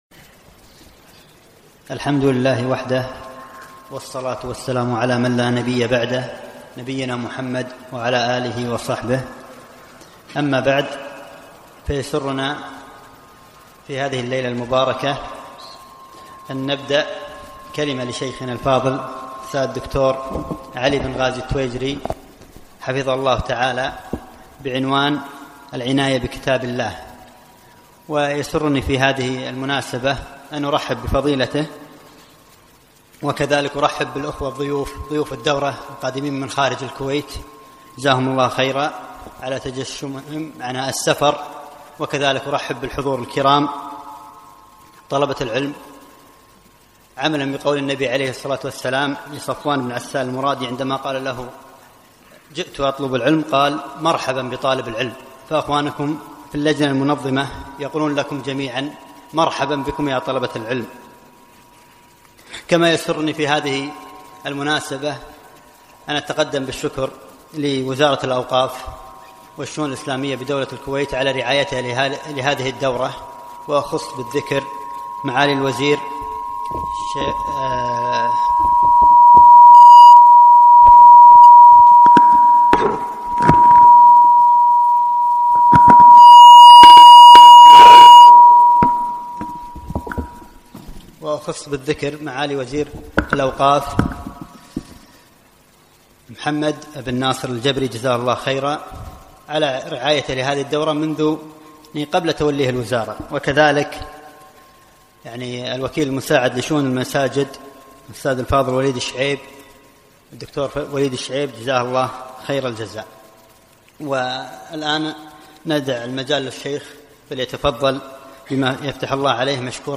العناية بكتاب الله - محاضرة